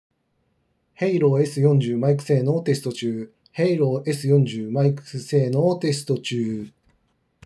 クリアで聴きやすいマイク性能
クリアな音声を相手に届けてくれます。